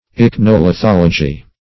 Search Result for " ichnolithology" : The Collaborative International Dictionary of English v.0.48: Ichnolithology \Ich`no*li*thol"o*gy\, n. [Gr.